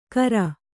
♪ kara